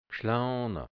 Mundart-Wörter | Mundart-Lexikon | hianzisch-deutsch | Redewendungen | Dialekt | Burgenland | Mundart-Suche: G Seite: 15